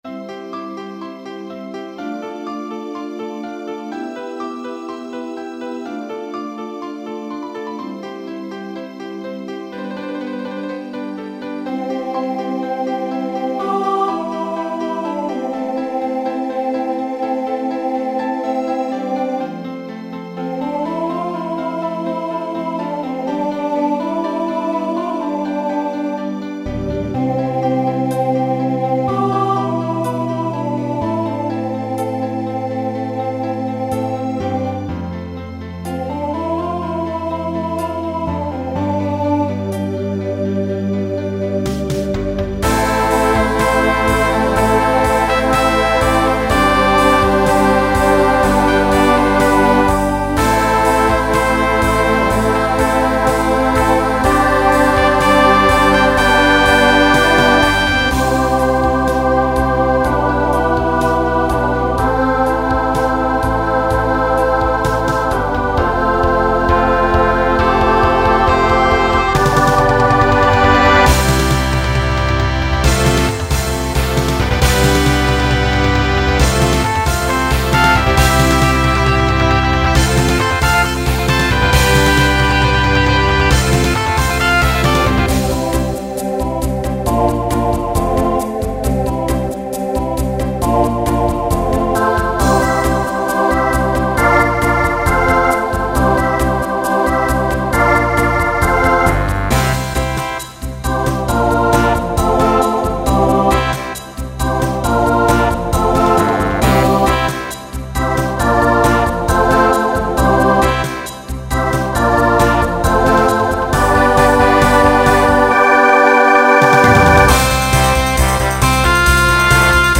Genre Pop/Dance , Rock
Opener Voicing SATB